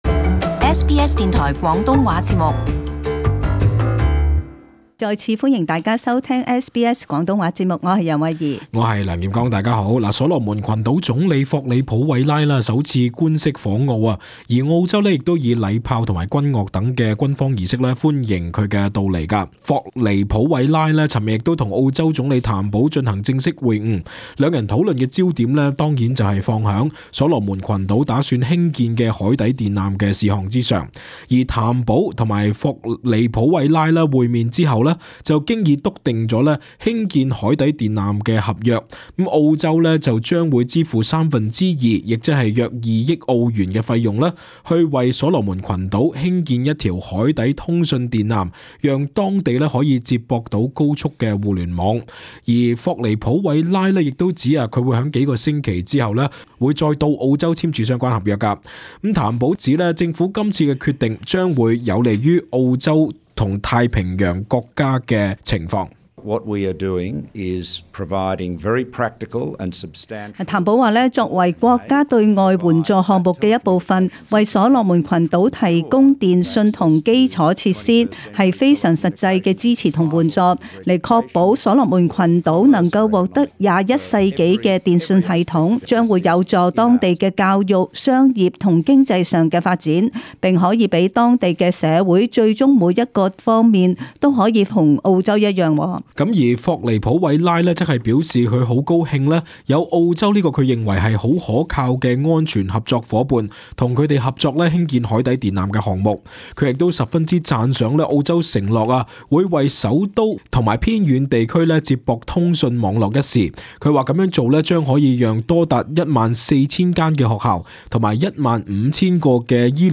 【時事報導】澳洲爭取為所羅門群島興建海底電纜協議